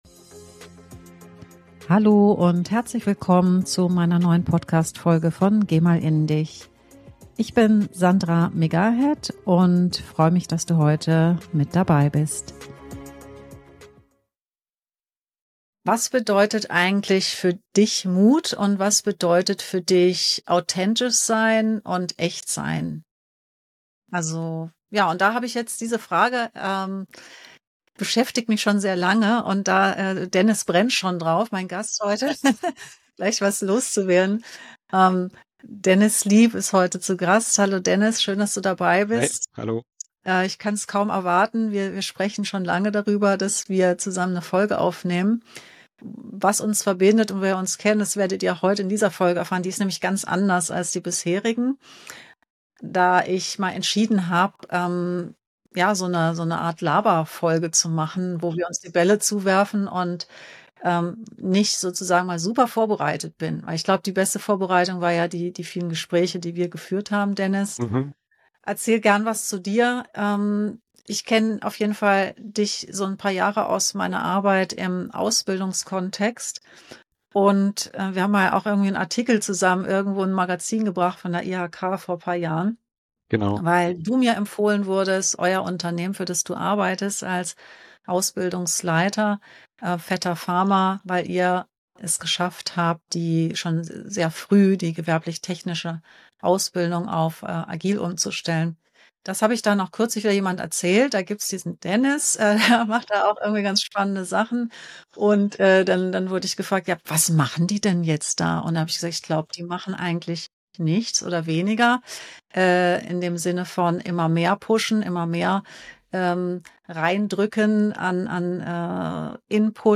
Ein ruhiger Deep Talk über Balance , Verantwortung und das Auftanken auf Reisen , mit dem Meer als stiller Begleiter. Ein tief gehendes Gespräch über Verantwortung, Nähe und Abstand, und darüber, wie das Meer helfen kann, wieder bei sich anzukommen.